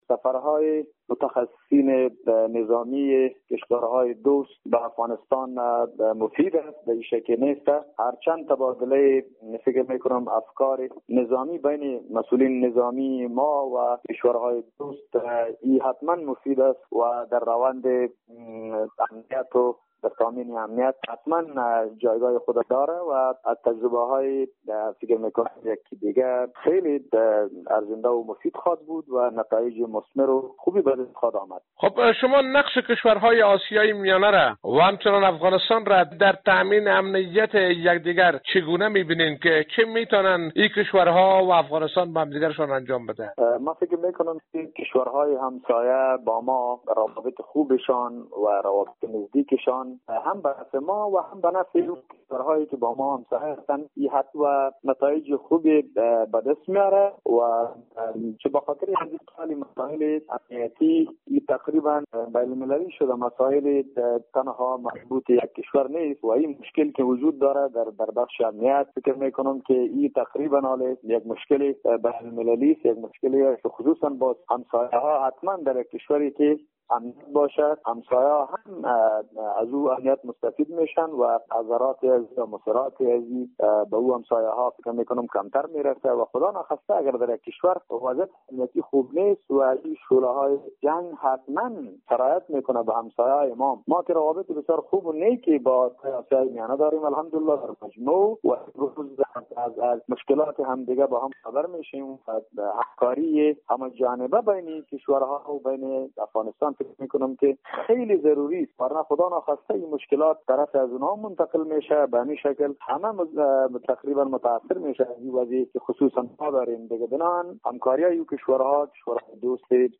در گفت و گو با خبرنگار رادیو دری